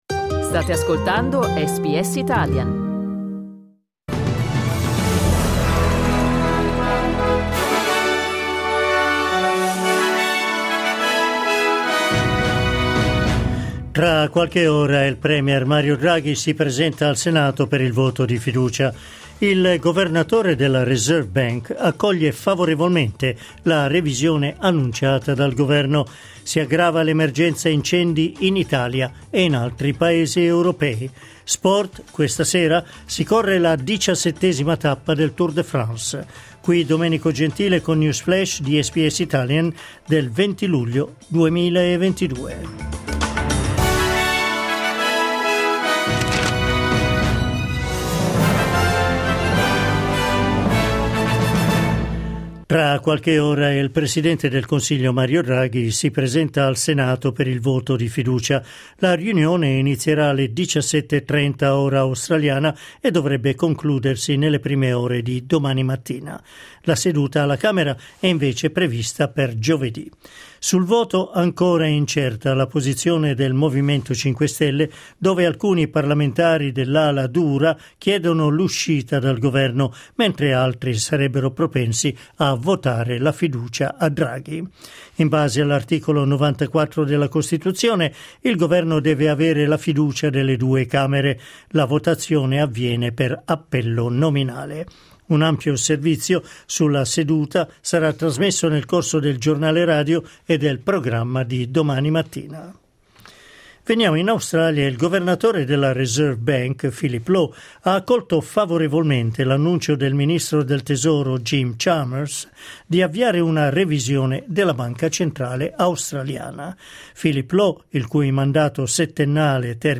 L'aggiornamento delle notizie di SBS Italian.